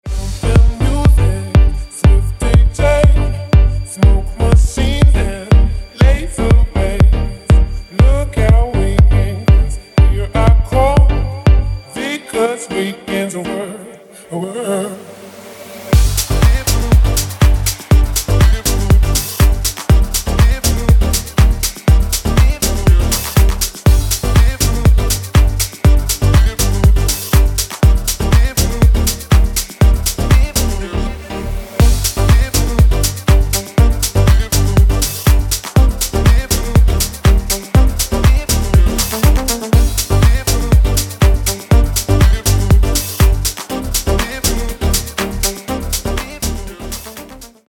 • Качество: 160, Stereo
мужской вокал
deep house
Club House